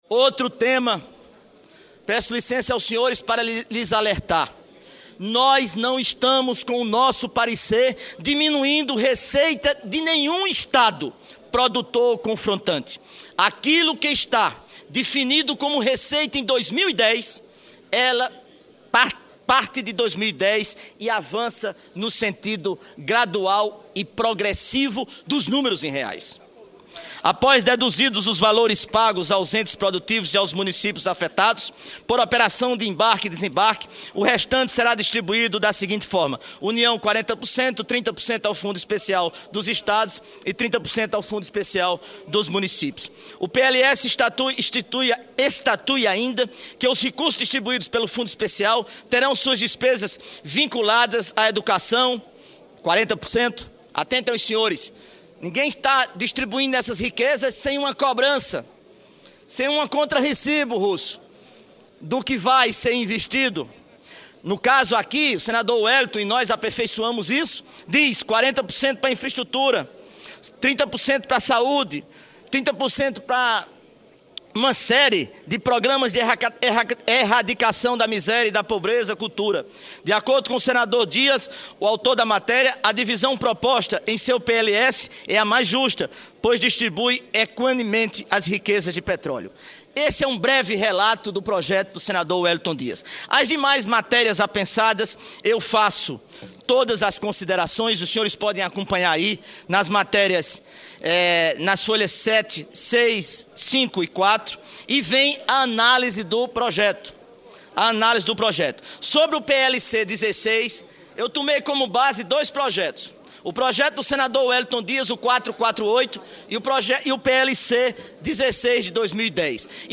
Pronunciamento do relator da matéria senador Vital do Rêgo - 2ª parte